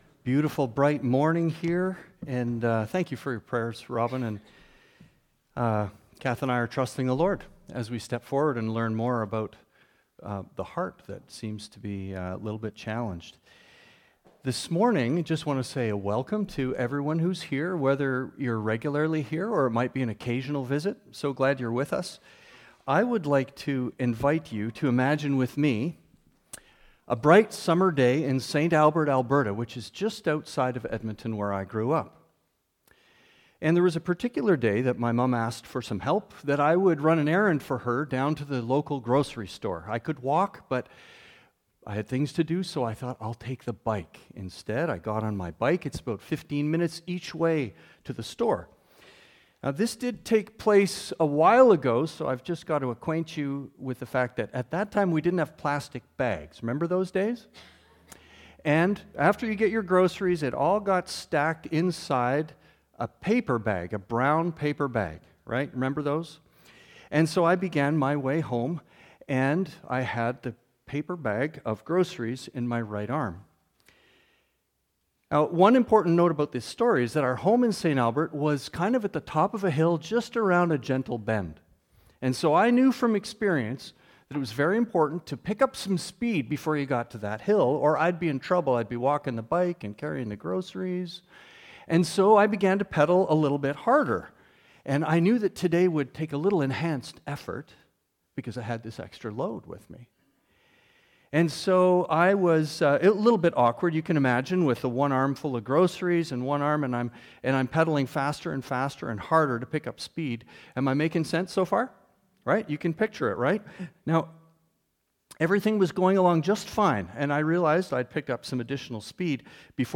Sunday morning sermons
JSAC_March_1_Sunday_Morning.mp3